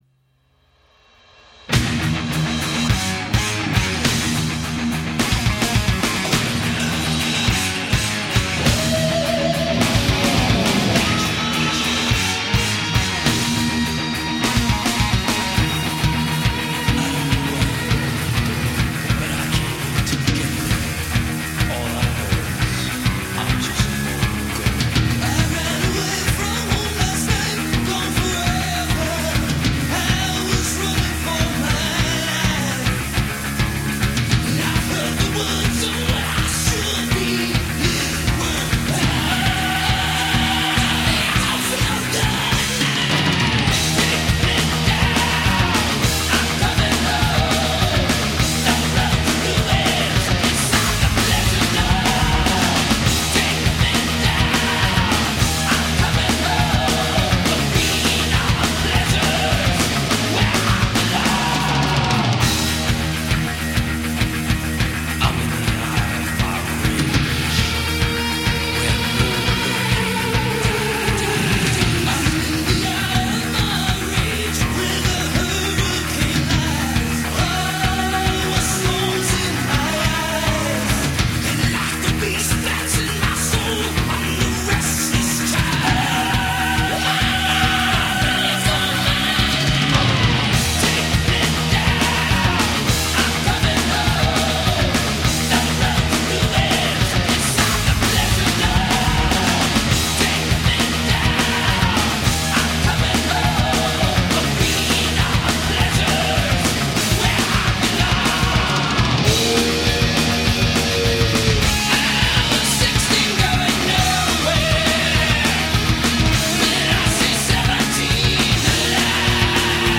genre: cris metal/heavy metal